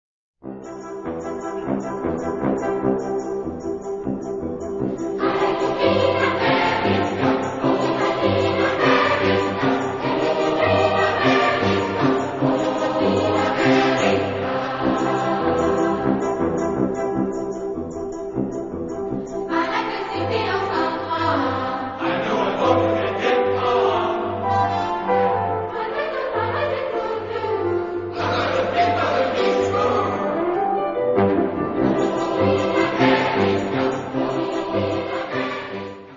Genre-Style-Forme : Chanson ; Profane ; Comédie Musicale
Type de choeur : SATB  (4 voix mixtes )